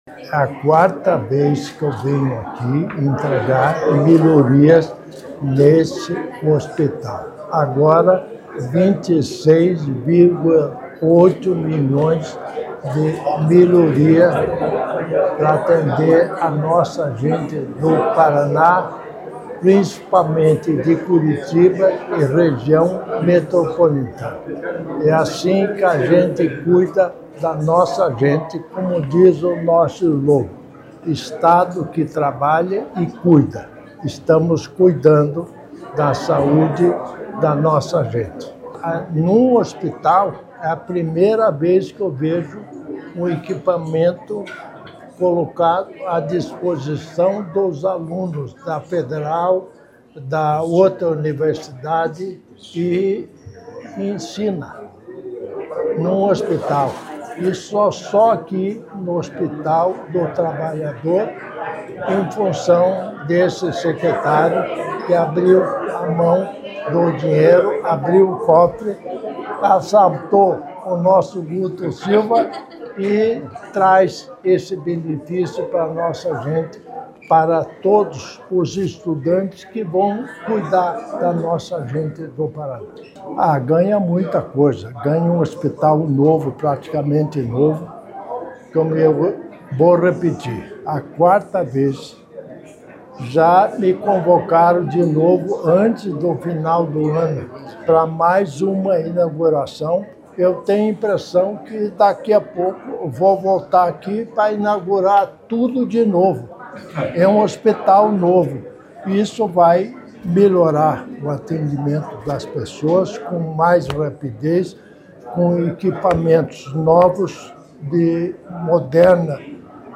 Sonora do governador em exercício, Darci Piana, sobre a ampliação do Pronto-Socorro do Hospital do Trabalhador